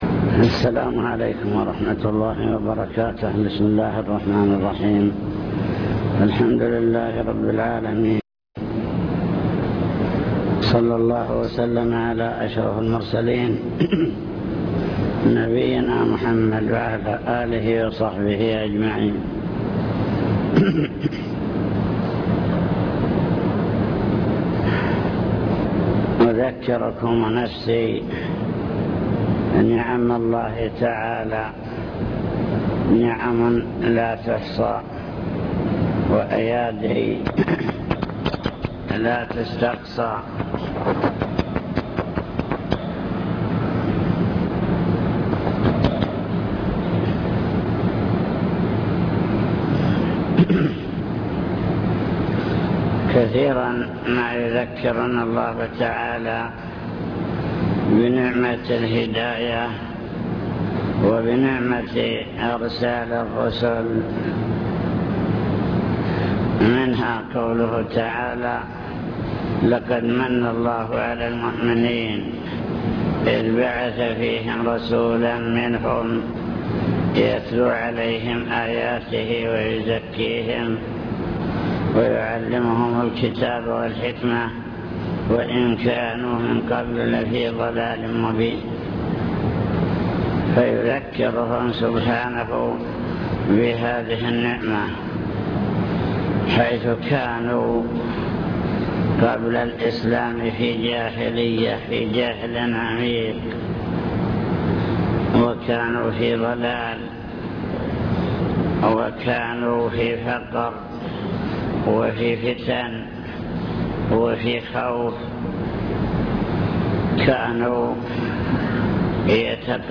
المكتبة الصوتية  تسجيلات - لقاءات  كلمة في مسجد نعم الله لا تحصى